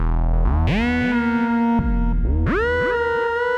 synth03.wav